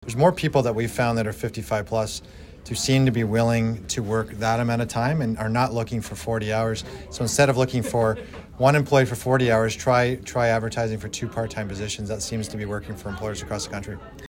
Bay of Quinte riding MP Ryan Williams told a meeting of the Quinte Economic Development Commission Thursday that employers might want to lower the number of hours of work offered in their jobs to lure at least one age group back into the labour force.